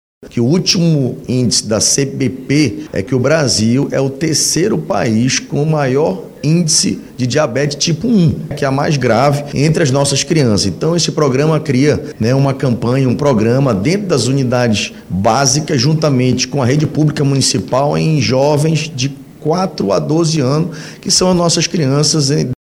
O vereador Diego Afonso, autor da iniciativa, explica como ela vai funcionar na prática.